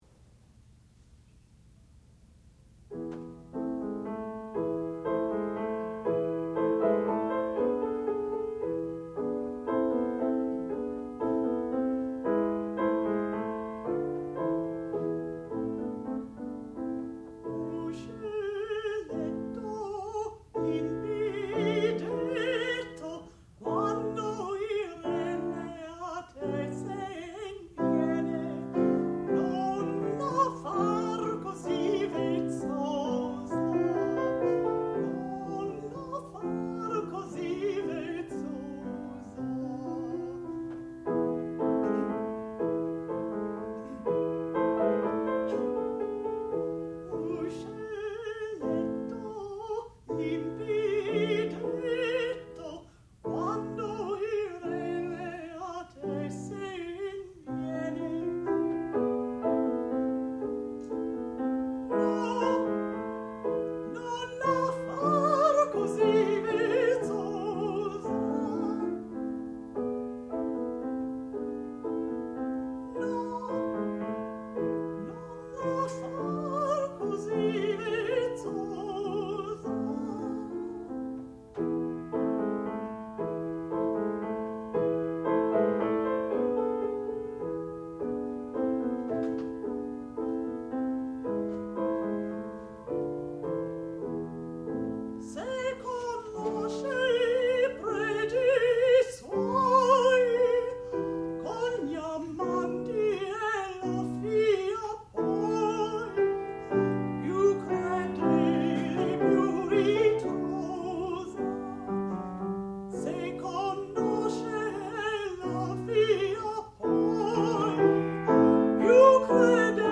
sound recording-musical
classical music
contralto
piano
Master's Degree Recital